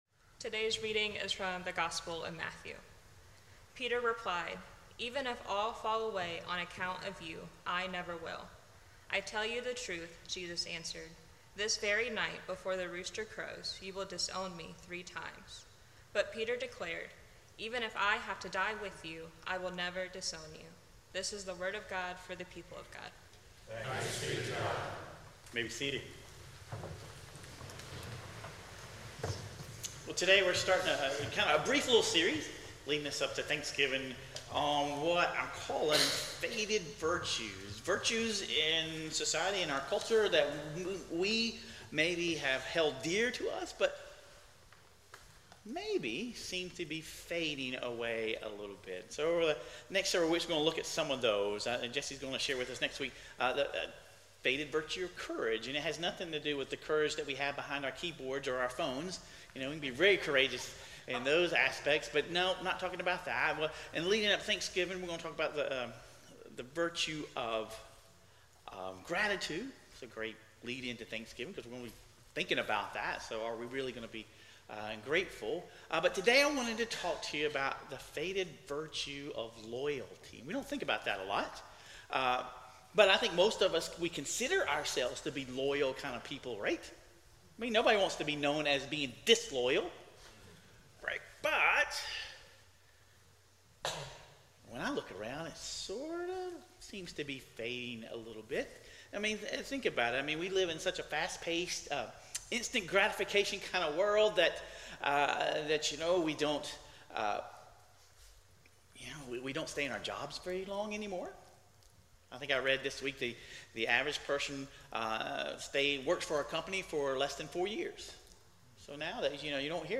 Sermon Reflections: